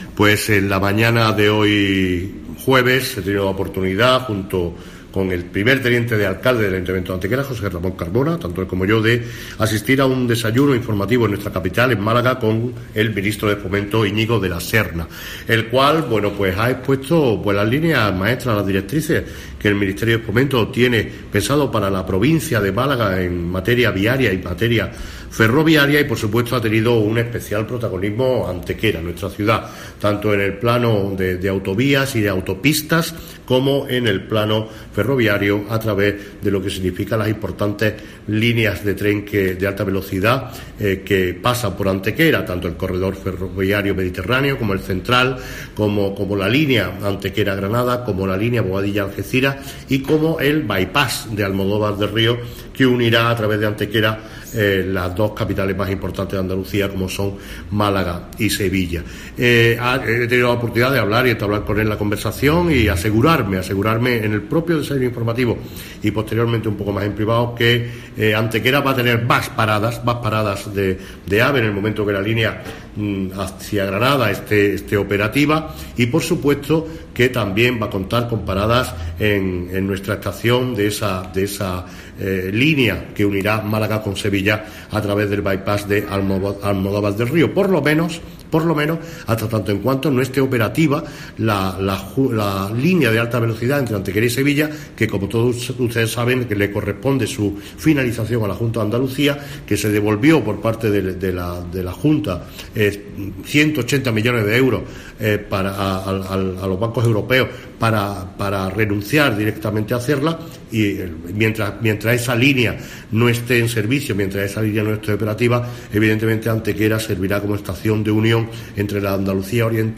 Manolo Barón se muestra, por su parte, satisfecho de la relevancia de Antequera como nexo de unión ferroviario entre el este de Andalucía y Sevilla, lo que se traducirá en nuevas paradas AVE con más destinos a su vez. Cortes de voz M. Barón 1256.43 kb Formato: mp3